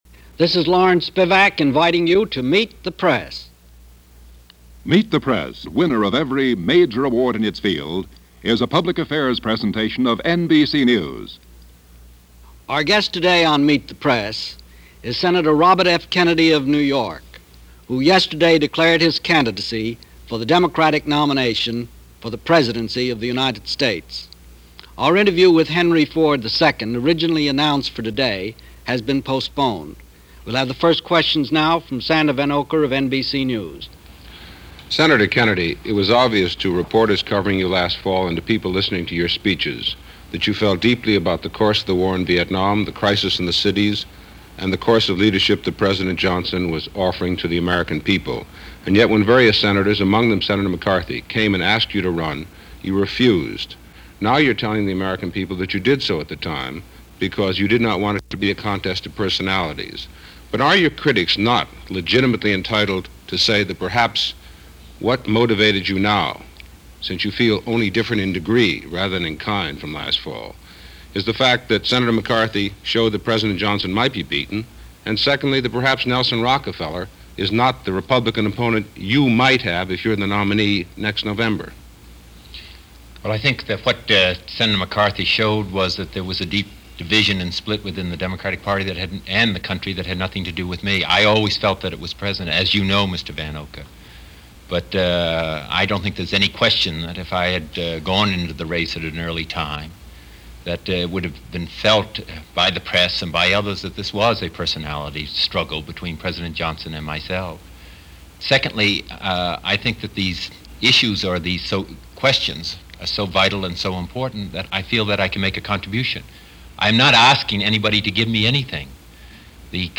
Since it was an election year, speculation was rife as to what the outcome was going to be. As of the date of this Meet The Press Interview, Sen. Robert F. Kennedy announced his decision to run the day before, and President Johnson hadn’t yet announced his decision not to run.